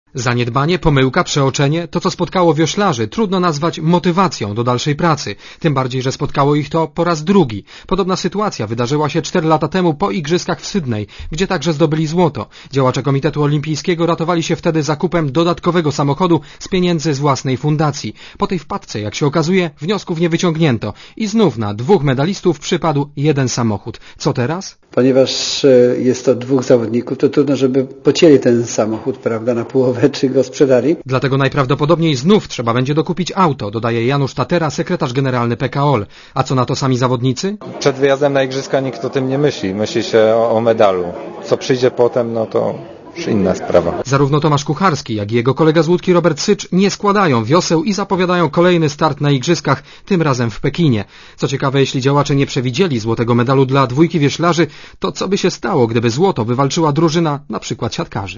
Źródło zdjęć: © PAP 14.09.2004 | aktual.: 14.09.2004 17:53 ZAPISZ UDOSTĘPNIJ SKOMENTUJ Relacja reportera Radia ZET